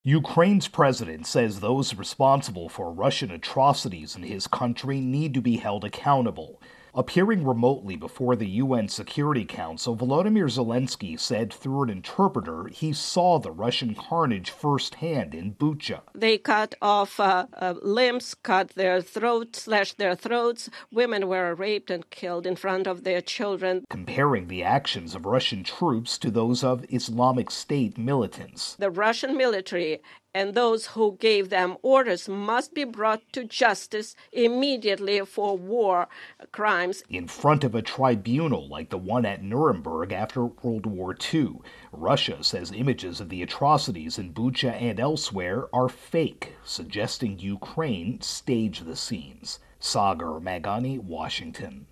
Russia-Ukraine War intro and wrap.